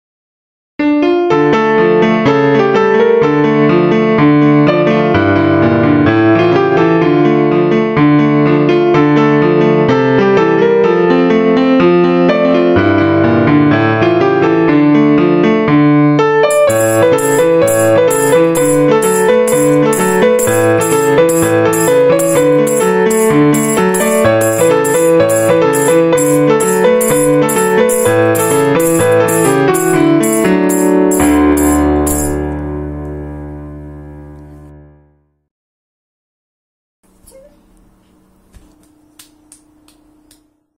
на фортепиано